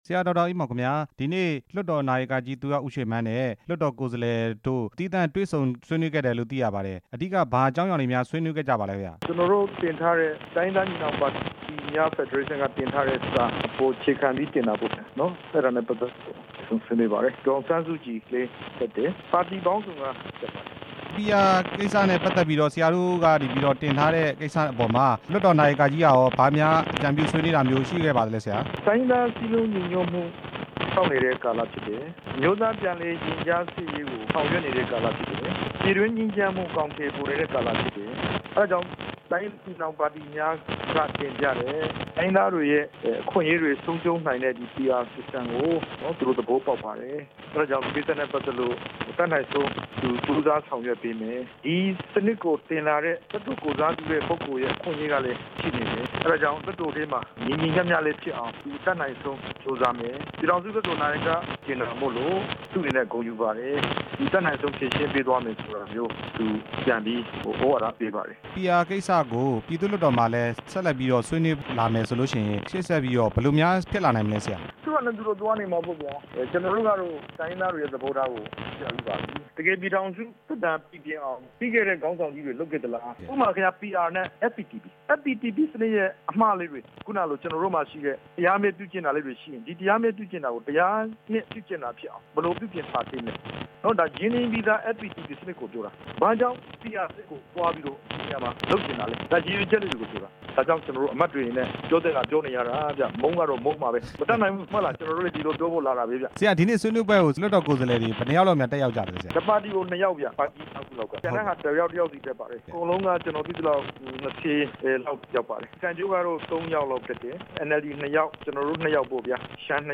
ဒေါက်တာအေးမောင်နဲ့ မေးမြန်းချက်